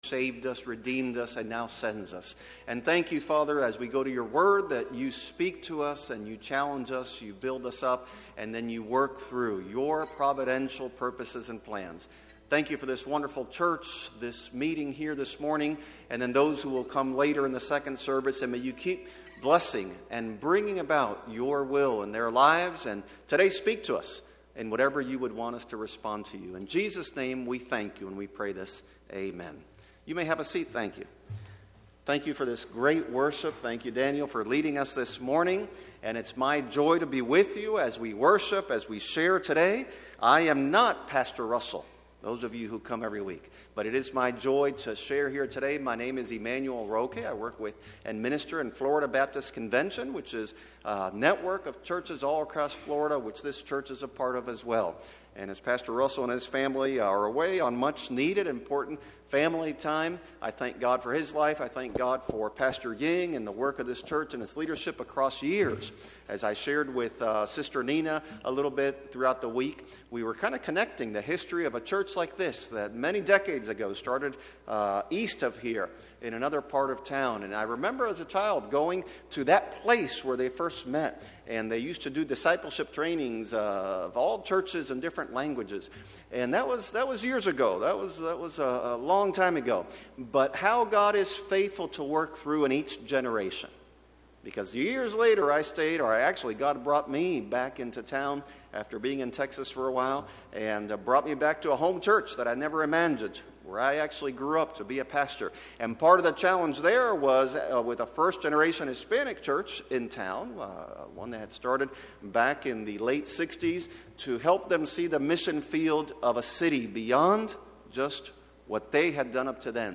A message from the series "English Sermons."